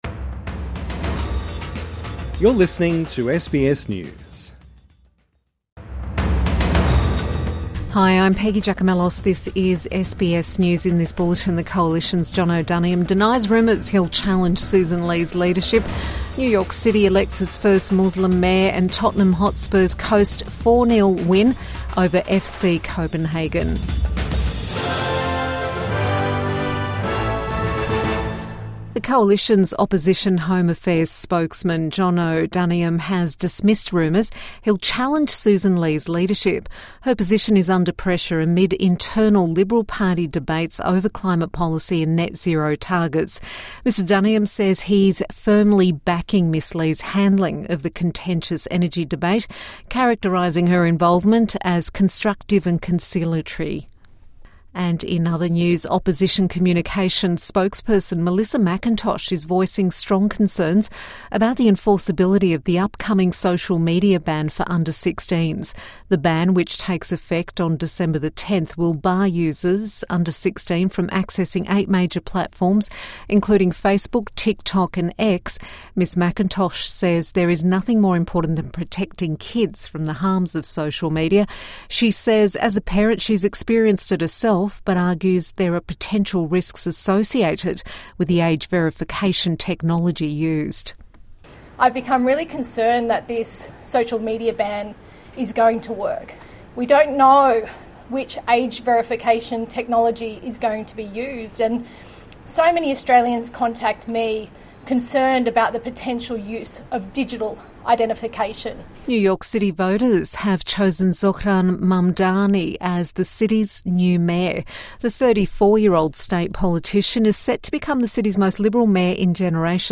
Coalition MP denies rumours of leadership challenge |Evening News Bulletin 5 November 2025